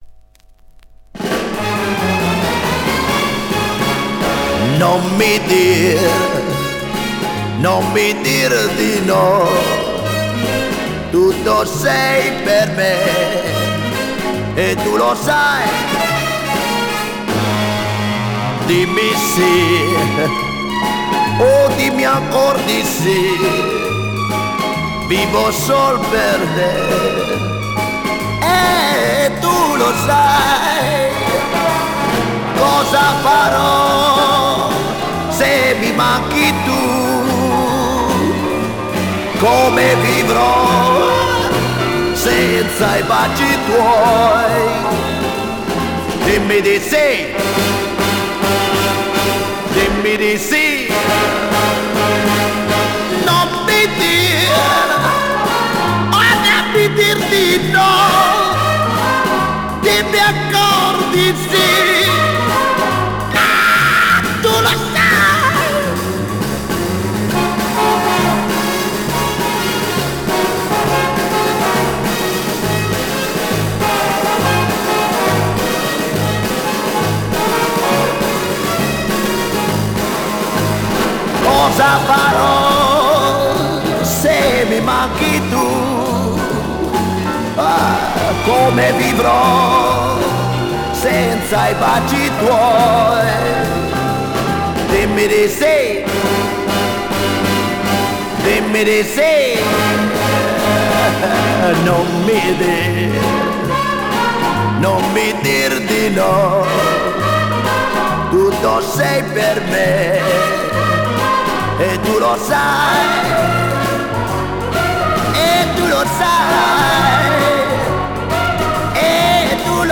Жанр: Rock, Funk / Soul, Pop
Стиль: Rock & Roll, Chanson, Pop Rock, Ballad